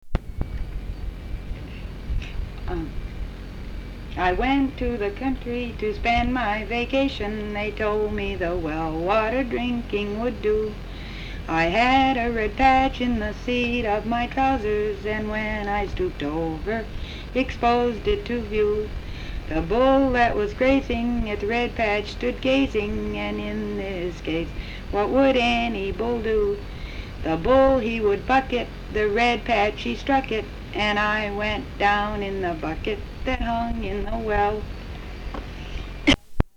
Folk songs, English--Vermont
sound tape reel (analog)
Brattleboro, Vermont